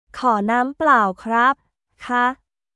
コー ナーム プラオ クラップ／カ